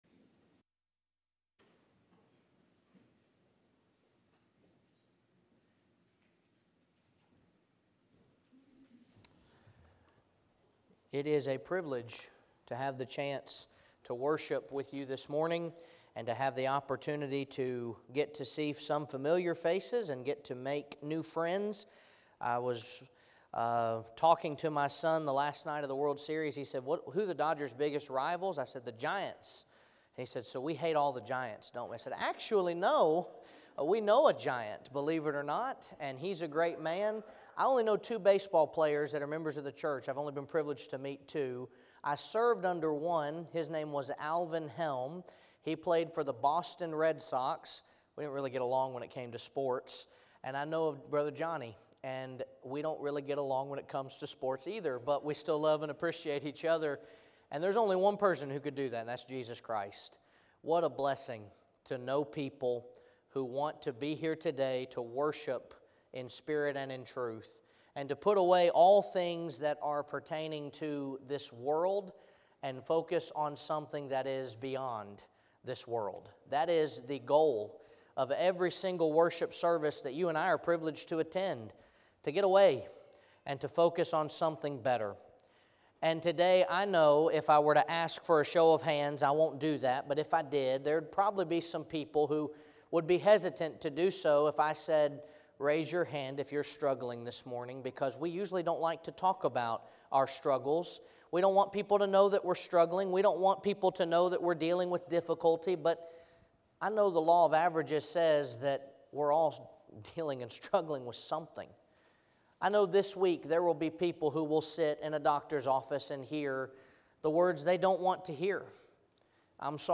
The sermon is from our live stream on 11/10/24